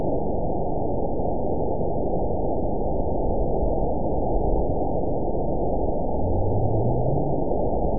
event 912041 date 03/16/22 time 22:55:46 GMT (3 years, 2 months ago) score 8.13 location TSS-AB02 detected by nrw target species NRW annotations +NRW Spectrogram: Frequency (kHz) vs. Time (s) audio not available .wav